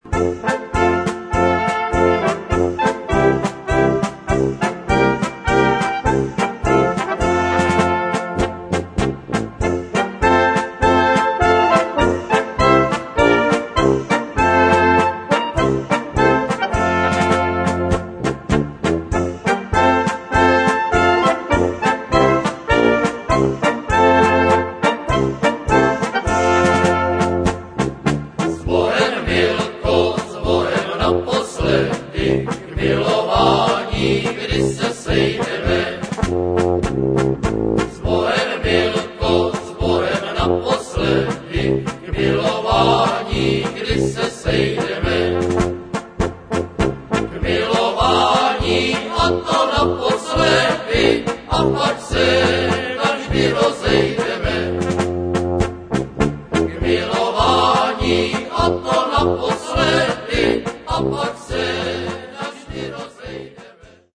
polka 7:32 -upr.